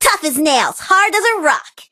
bibi_start_vo_03.ogg